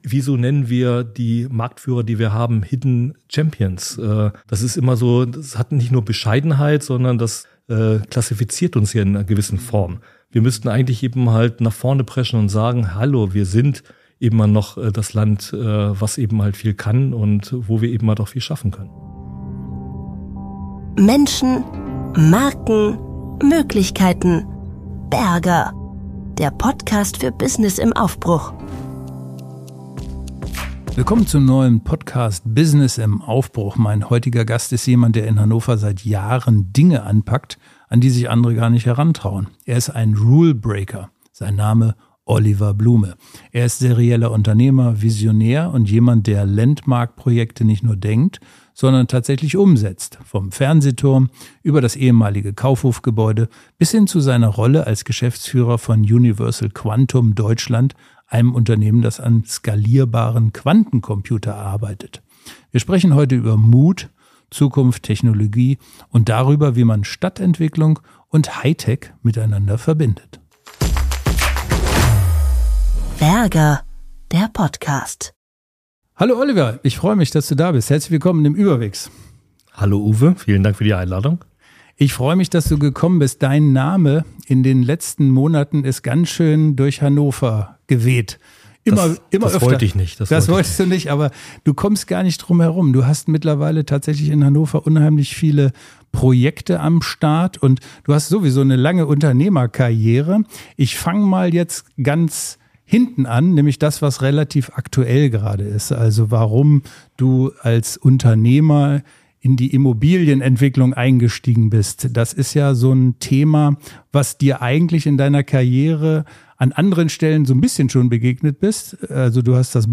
Ein Gespräch über Unternehmergeist, Regelbrüche, technologischen Wandel und echte Zukunftslust.